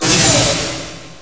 menu_next.mp3